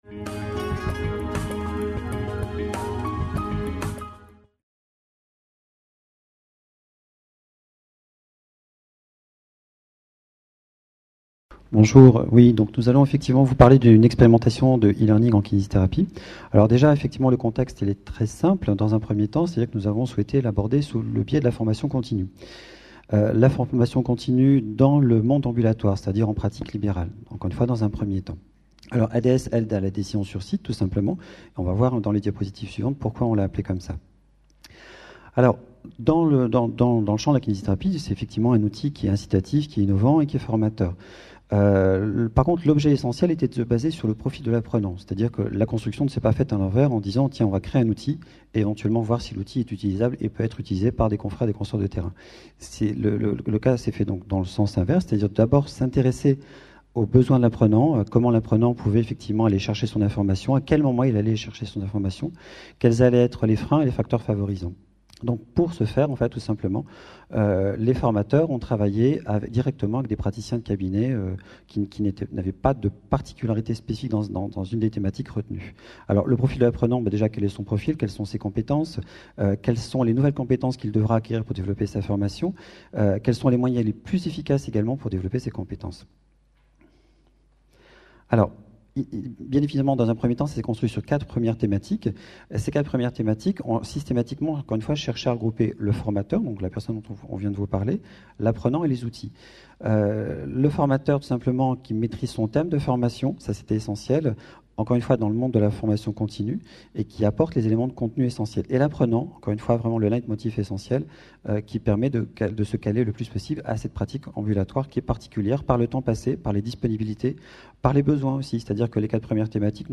FORMATIC - Paris 2011 : ADS - Aide à la Décision sur Site et e-learning en kinésithérapie. Conférence enregistrée lors du congrès international FORMATIC PARIS 2011. Atelier TIC et pratiques innovantes au service de la formation des professionnels de la santé.